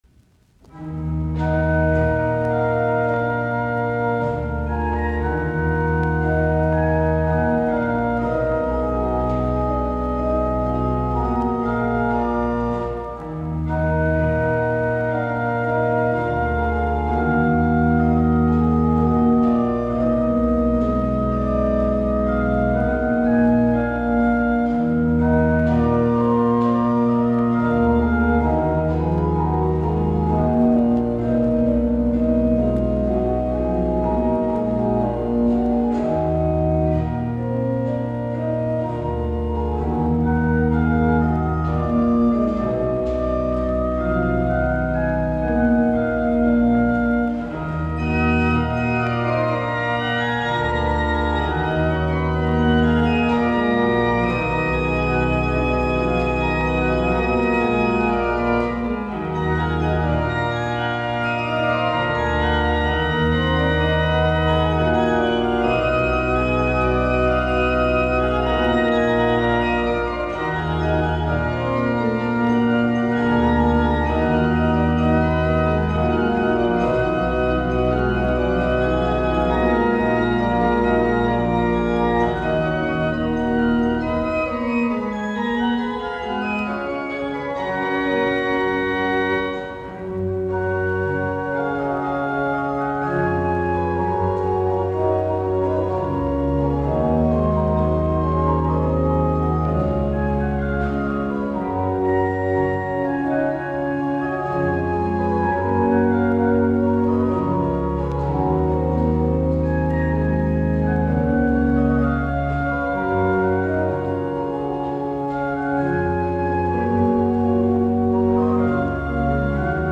Soitinnus: Urut.
Leufsta Bruk.